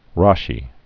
(räshē) Originally Solomon Bar Isaac. 1040-1105.